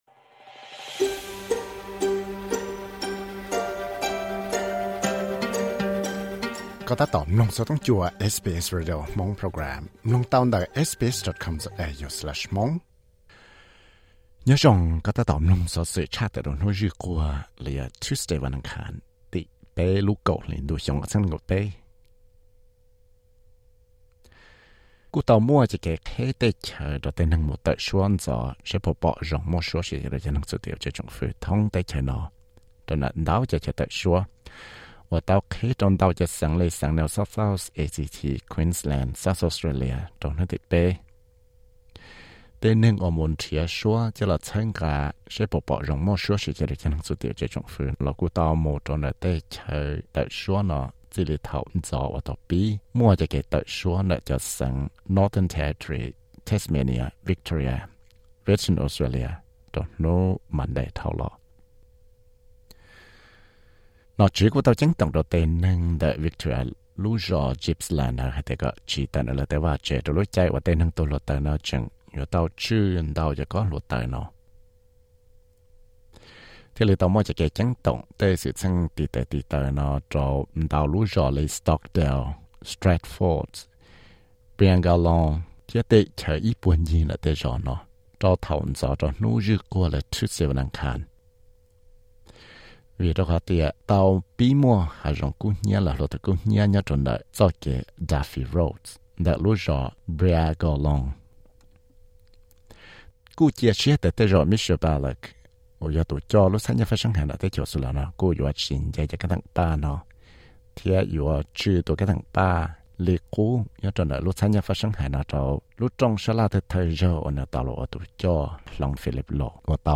Xov xwm luv hnub zwj Quag (Tuesday newsflash 03.10.2023): muaj cov kev tawm suab Voice Referendum ntxov ntawm ntau lub xeeev, Victoria cov ceeb toom hav zoov kub hnyiab, RBA cov kab theem paj, tshuaj Malaria vaccine, tswv yim daws Nplog tej tsheb daig, Thaib cov lagluam ntoj ncig thiab tej nyiaj digital wallet, Netherland rooj plaub uas nplua Apple.